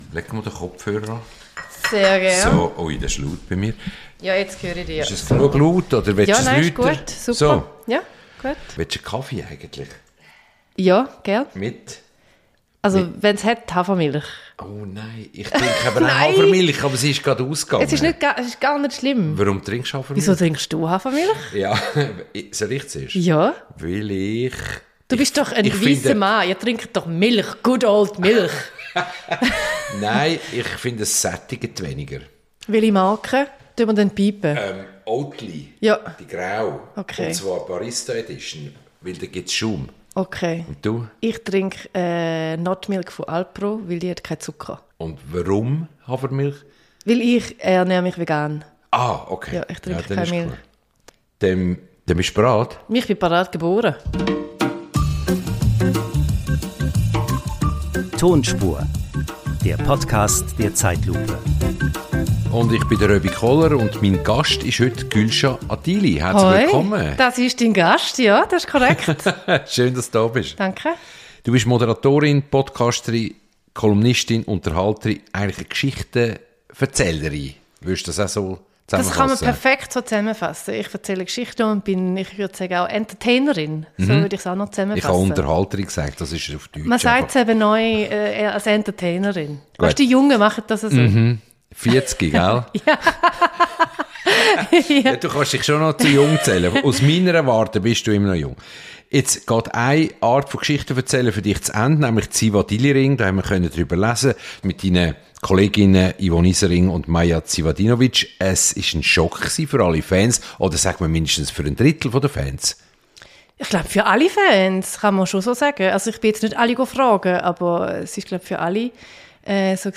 Im Gespräch mit Röbi Koller gewährt Gülsha einen Blick hinter die Kulissen und verrät, was sie antreibt und wo sie im Moment im Leben steht.